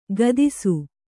♪ gadisu